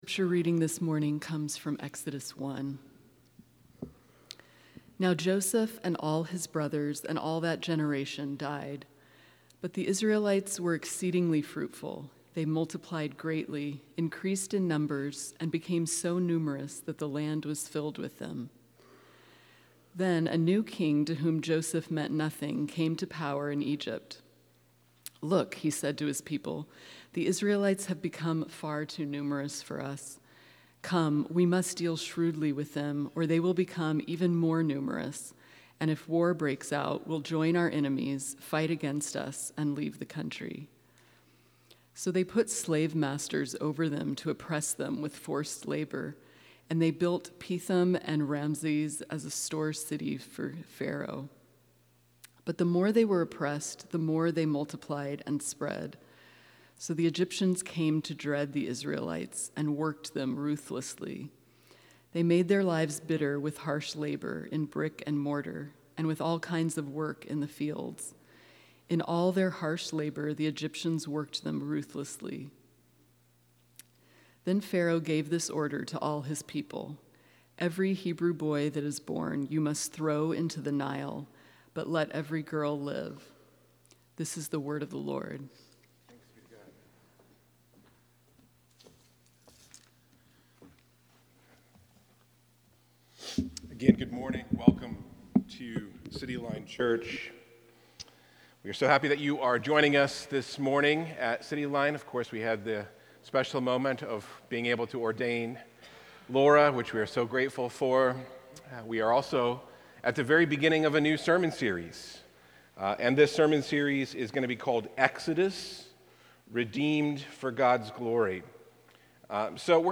This sermon explores how God remains faithful even when His people feel forgotten.